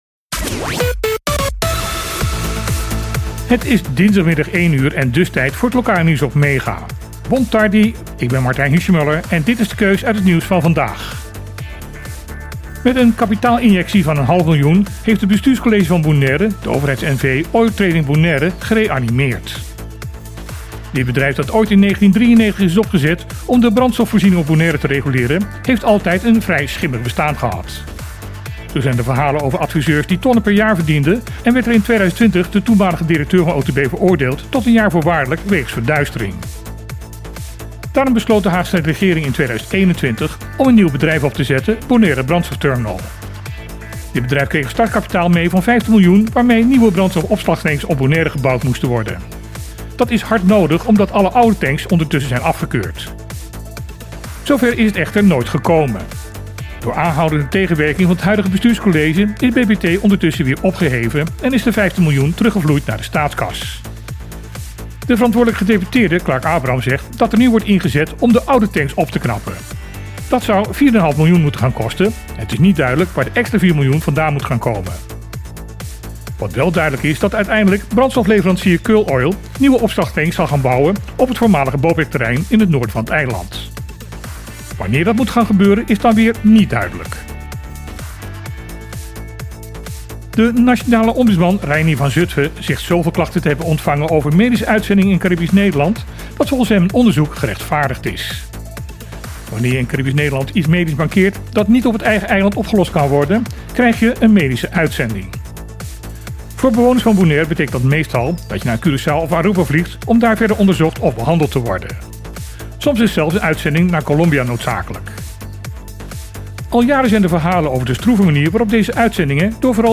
Een dagelijks bulletin met het lokale nieuws van Bonaire.